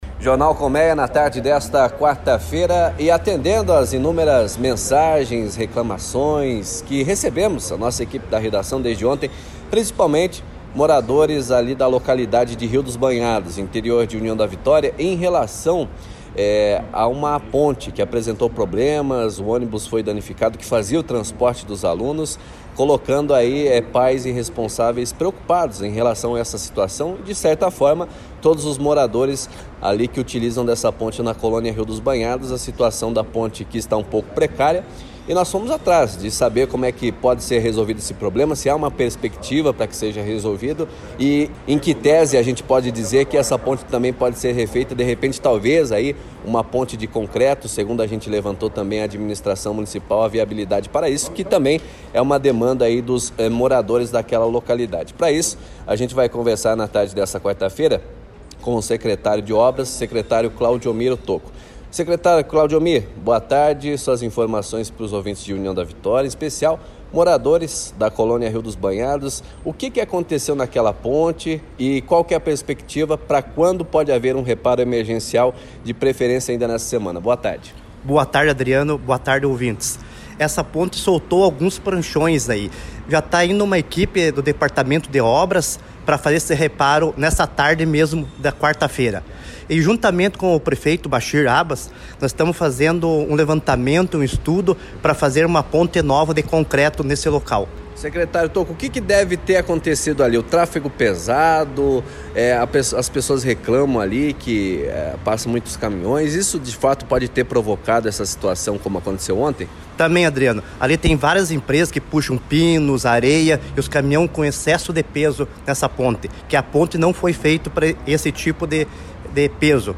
Acompanhe a fala do secretário de Obras abaixo: